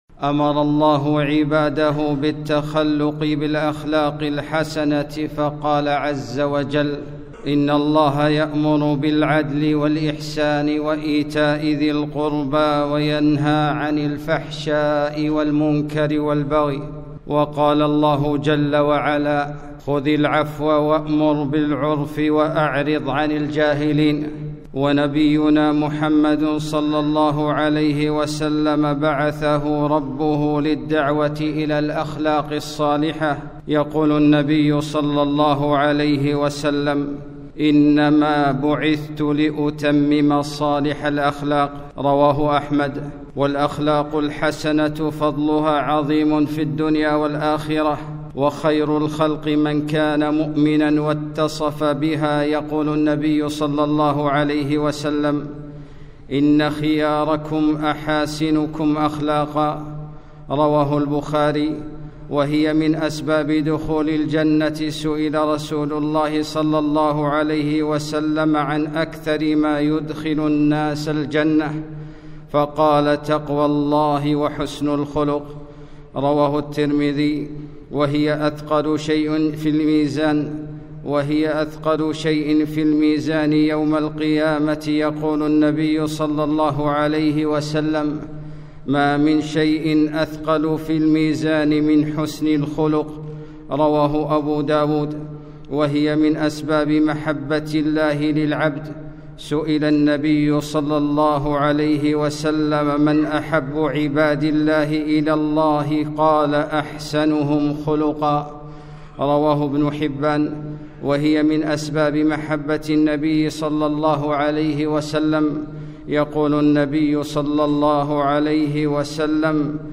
خطبة - ( وقولوا للناس حسنًا )